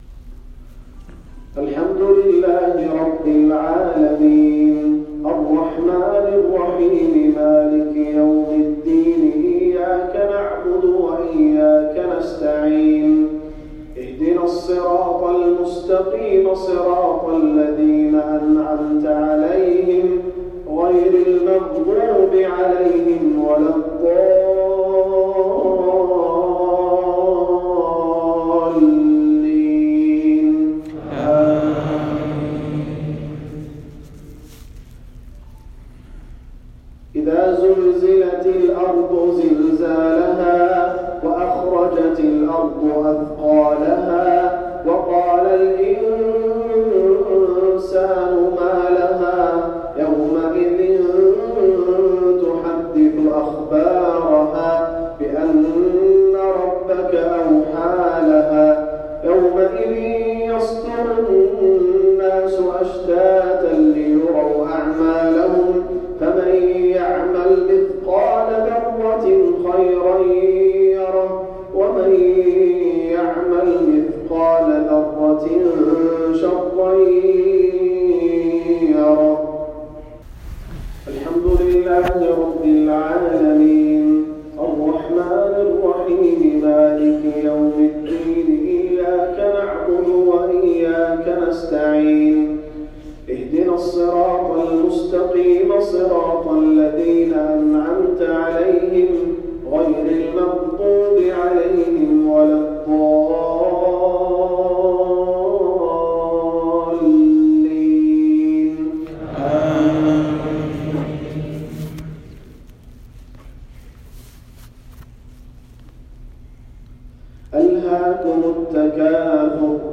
حصري - من تسجيلي تلاوة من صلاة الجمعة ٣٠ ربيع الأول ١٤٤٢هـ للشيخ سلطان القحطاني رئيس محكمة الجبيل